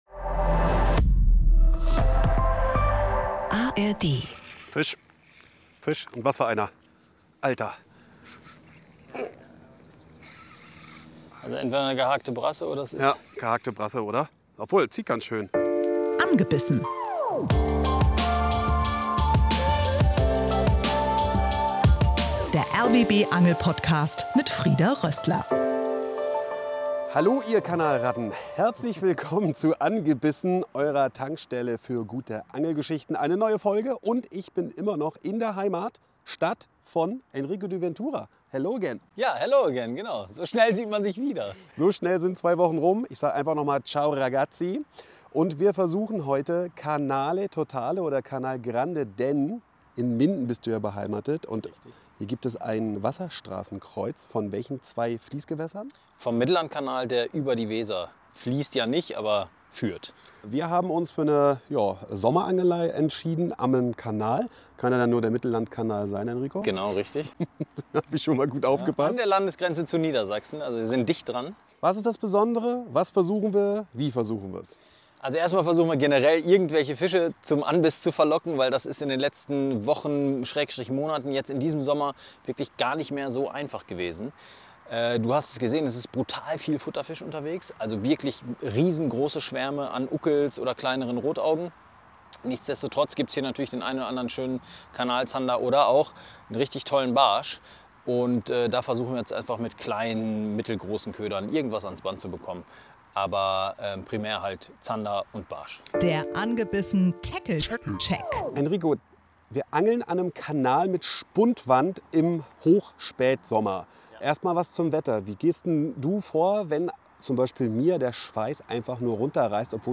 Die beiden angeln im Mittellandkanal, unweit vom Wasserstraßenkreuz mit der Weser. Der Plan: Zander und Barsche fangen.